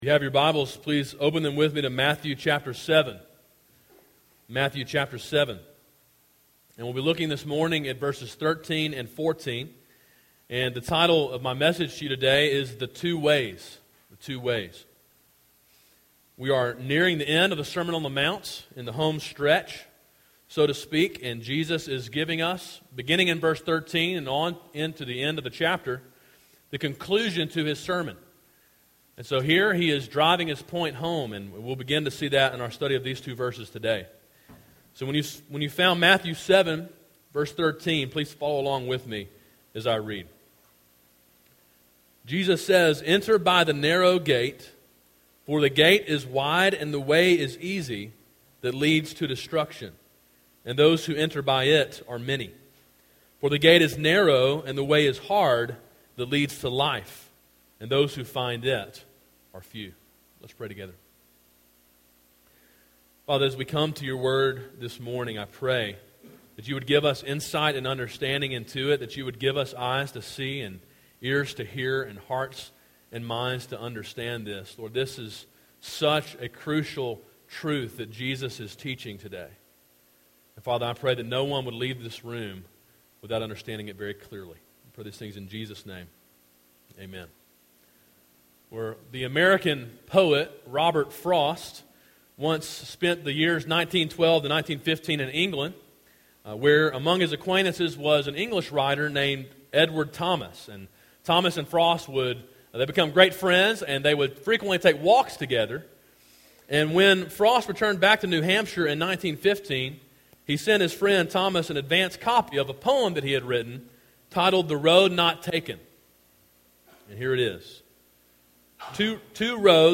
A sermon in a series titled Sermon on the Mount: Gospel Obedience.